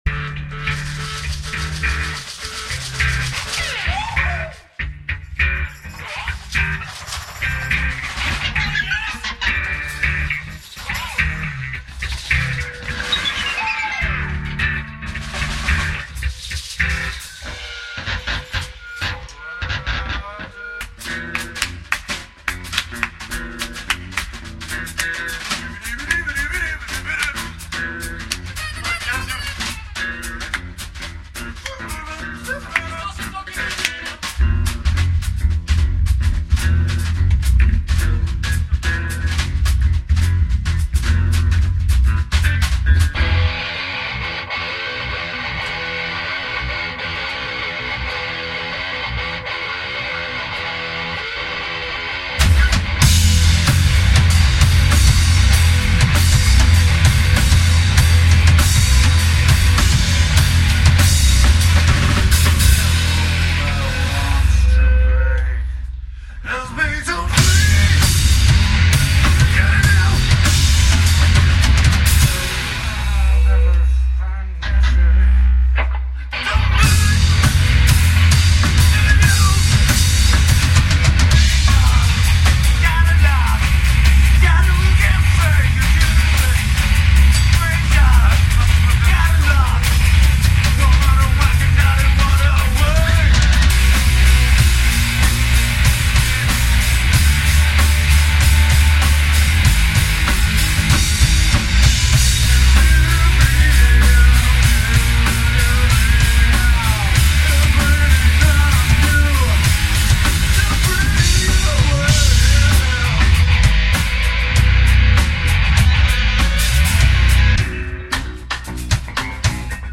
the first unedited medley from 2006.. - free live bootleg